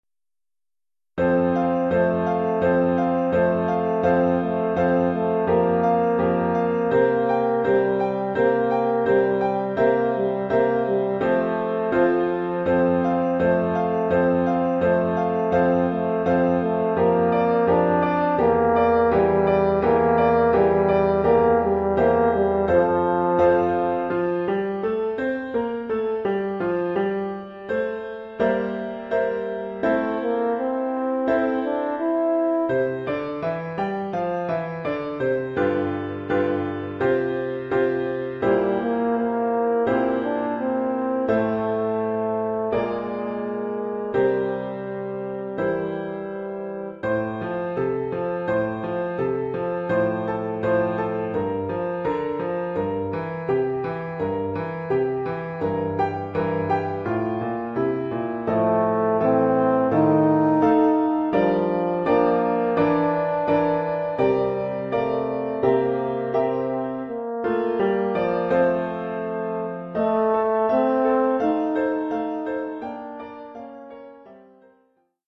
Oeuvre pour saxhorn alto mib et piano.
Niveau : débutant.